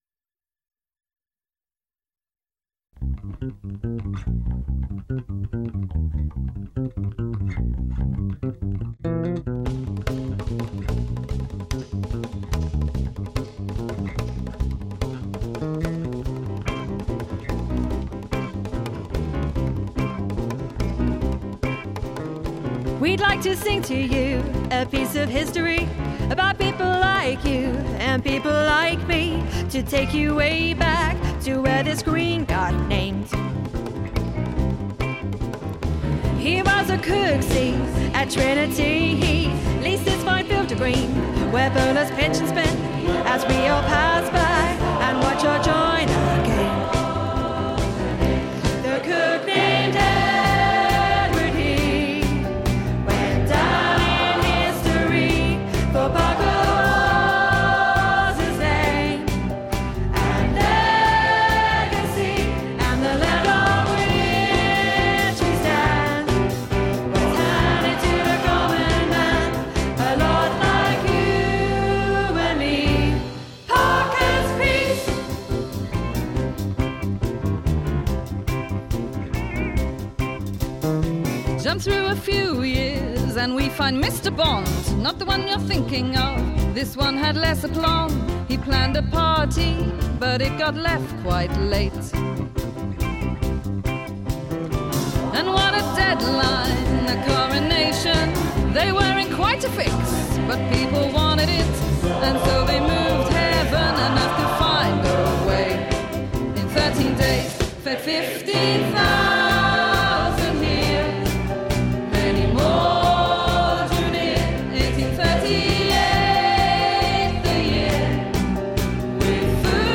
The songs will all be performed by local choirs, musicians and poets to make a free app for Cambridge to leave a lasting digital legacy.